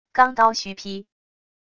钢刀虚劈wav音频